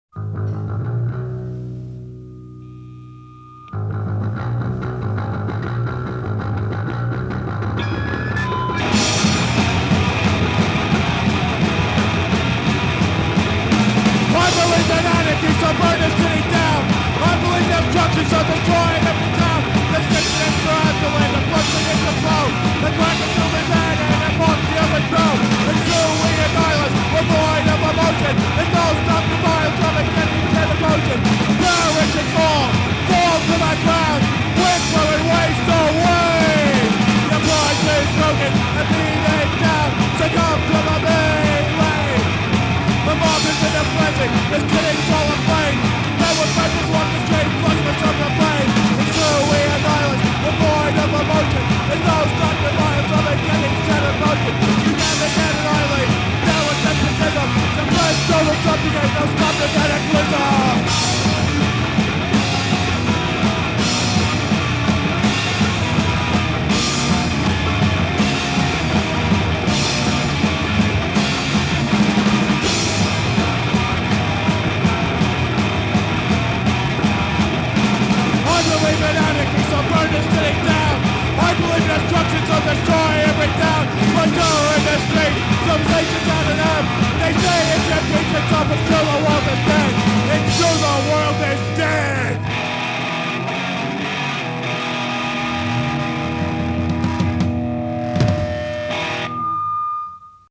Genre: Hardcore Punk